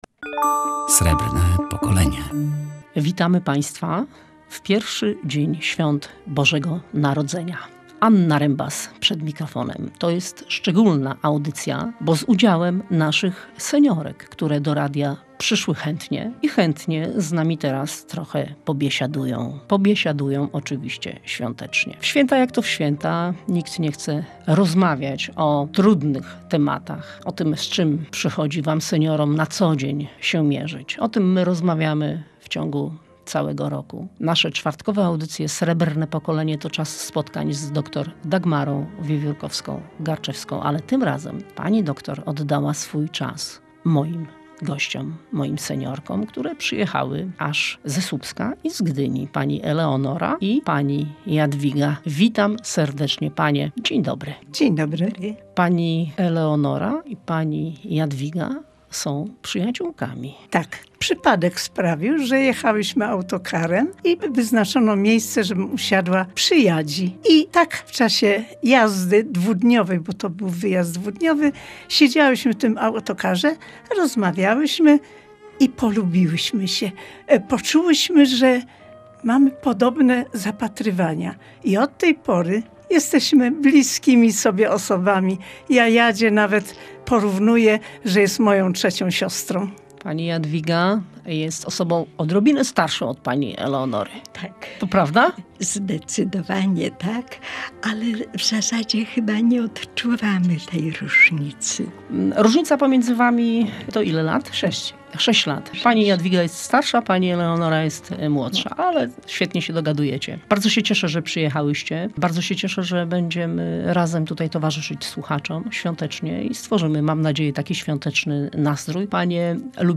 Razem na święta – Boże Narodzenie pełne wspomnień. Seniorki z Pomorza gośćmi Radia Gdańsk
Seniorki z Pomorza przyjechały specjalnie do radia, aby wspólnie z Państwem świętować oraz podzielić się opowieściami o swoich świętach i bożonarodzeniowych wspomnieniach sprzed lat.